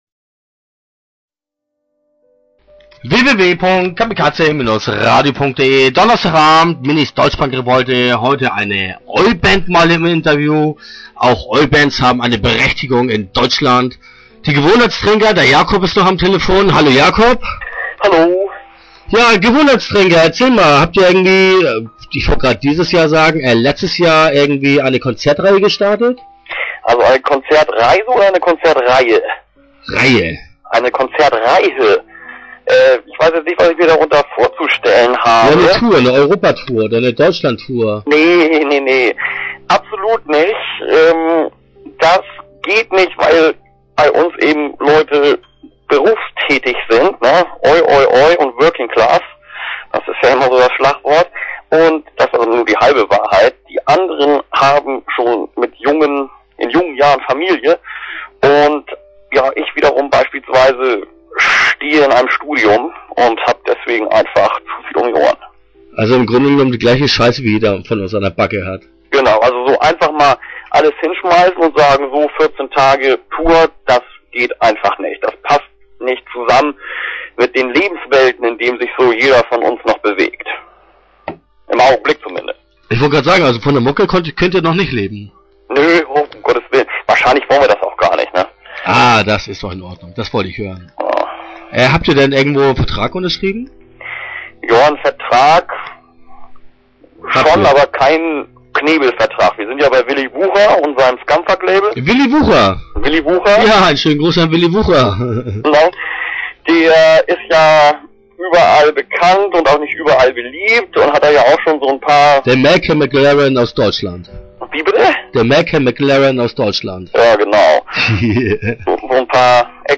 Interview Teil 1 (9:54)